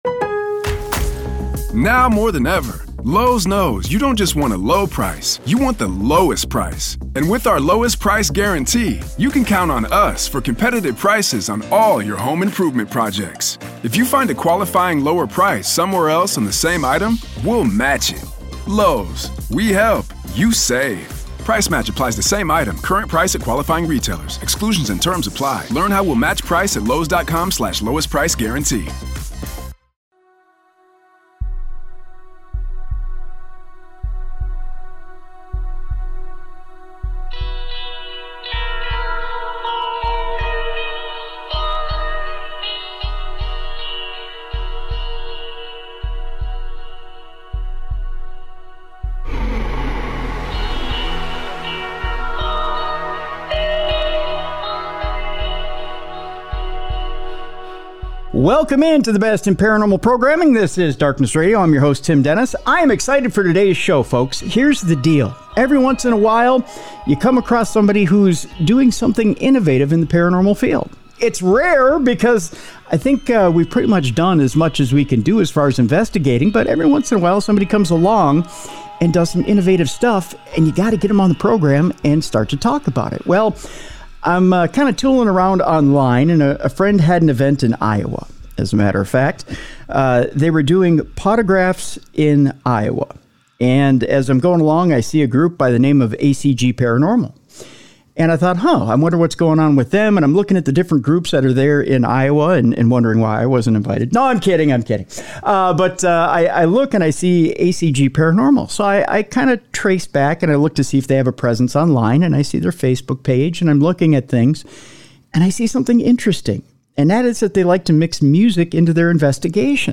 Darkness Radio presents: The Sounds of Spirits: Using Music In Paranormal Investigation with paranormal investigators. ACG Paranormal!